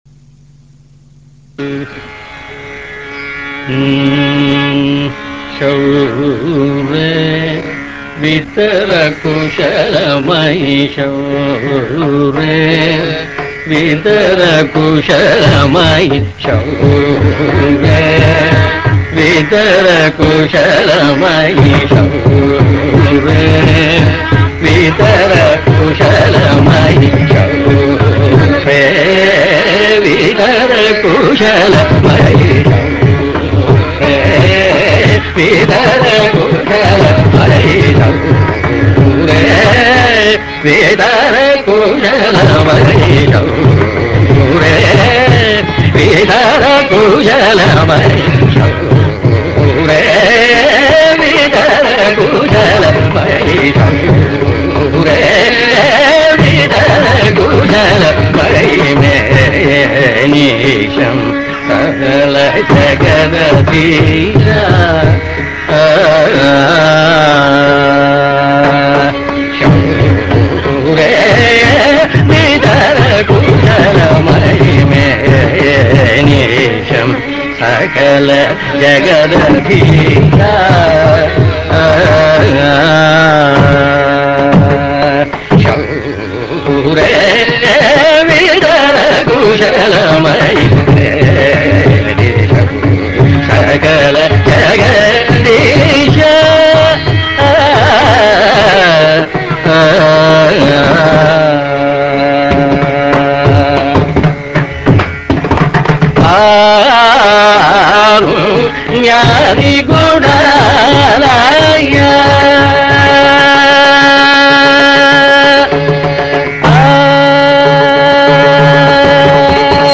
in Durbar Raaga Alapana followed by
Ragam Krithi and Kalpanaswarams.